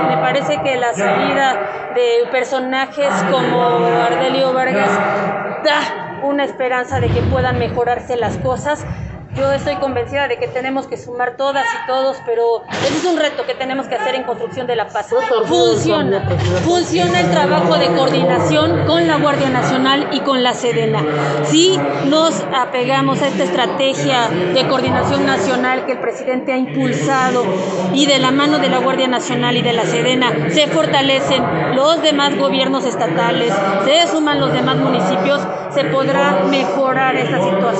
En entrevista, Rivera Vivanco abundó, con relación a los festejos que se tienen programados como cada año para el próximo miércoles 15 de septiembre, que por el momento aún no se contempla el que haya verbena popular, por lo que se está en espera a que avancen los días, y si se llegará a publicar un nuevo decreto por parte del Gobierno del Estado para no efectuar las actividades con ciudadanos, así se hará.